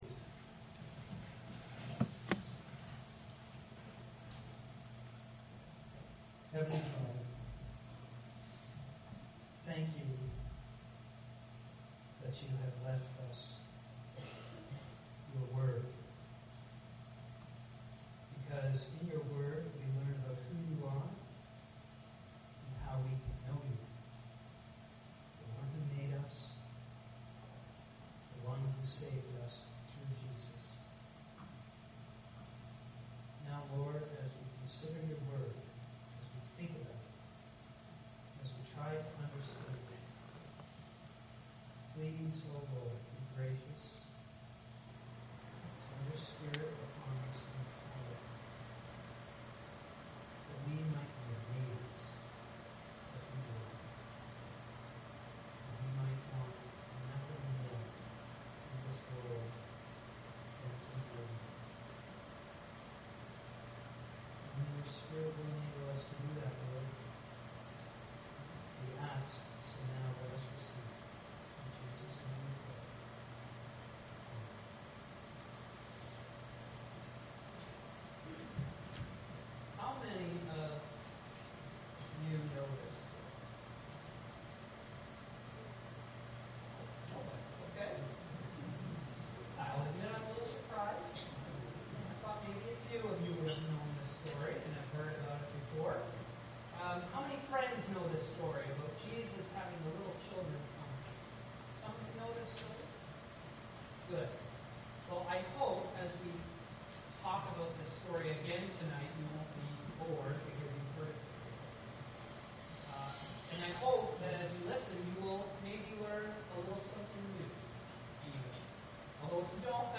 Mark 10:13-16 > During the Friendship service, we will study the wonderful story of Jesus and the little children.